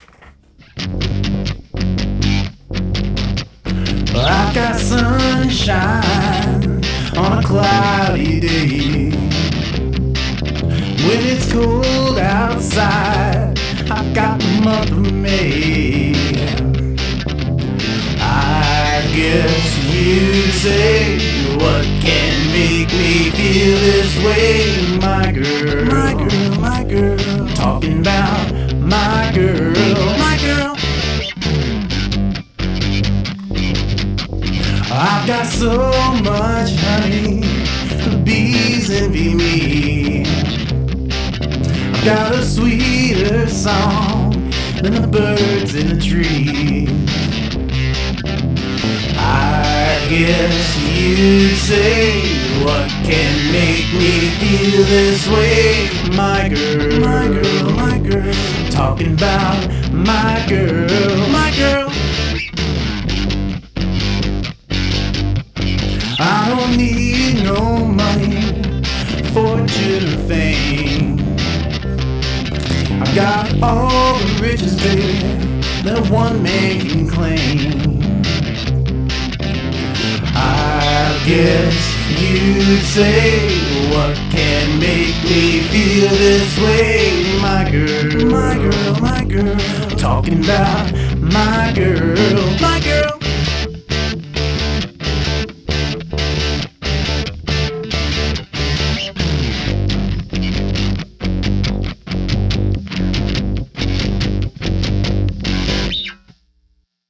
This is a totally left field approach to covering this song!
Way leftfield and way cool !!